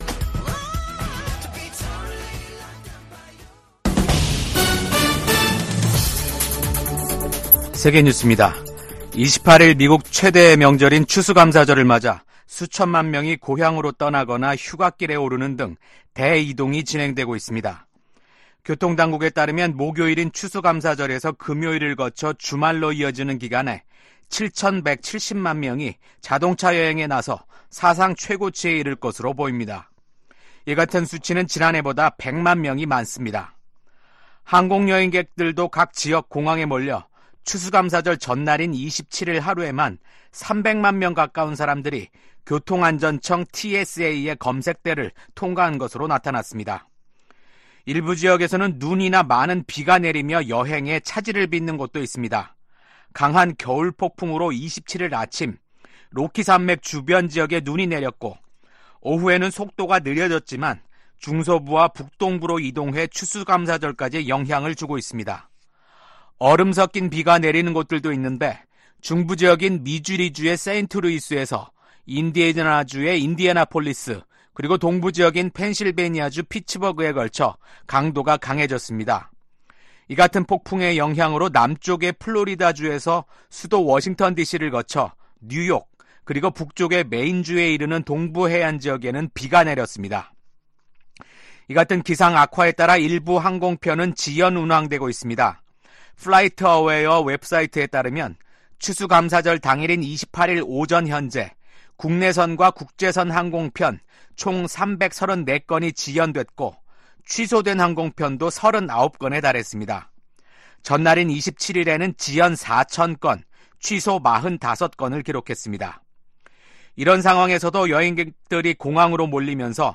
VOA 한국어 아침 뉴스 프로그램 '워싱턴 뉴스 광장'입니다. 우크라이나 사태를 주제로 열린 유엔 안보리 회의에서 미국 대표는 북한을 향해 러시아 파병이 사실이냐고 단도직입적으로 물었고, 북한 대표는 북러 조약 의무를 충실히 이행하고 있다며 이를 우회적으로 시인했습니다. 러시아와 전쟁 중인 우크라이나 특사단이 윤석열 한국 대통령 등을 만났지만 한국 측은 무기 지원에 신중한 입장을 보였습니다.